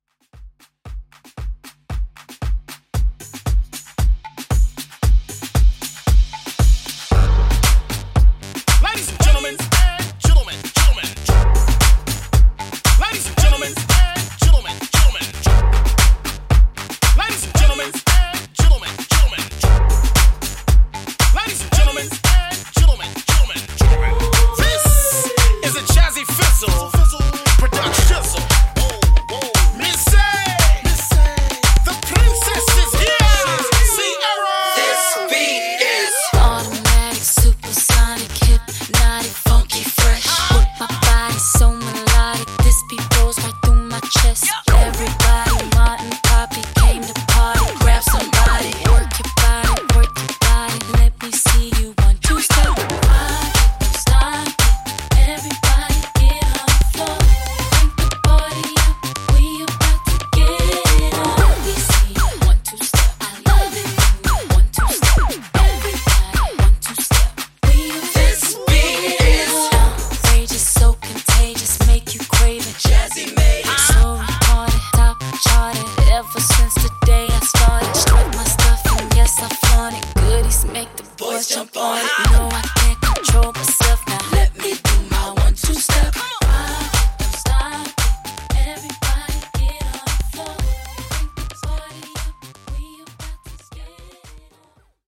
R&B Moombah)Date Added